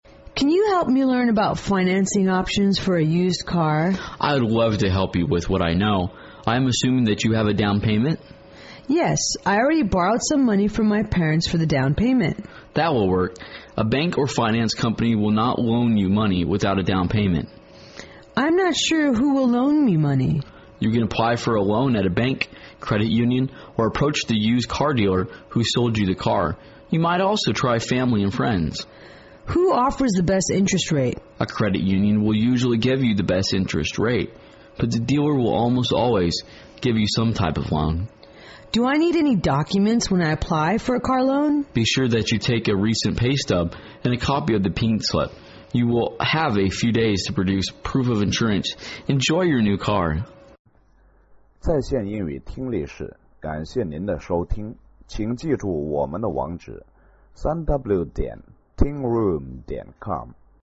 英语情景对话-How to Pay for the Used Car(2) 听力文件下载—在线英语听力室